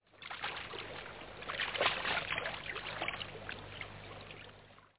鱼游声音效(1)~1.mp3